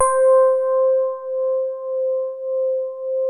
FINE SOFT C4.wav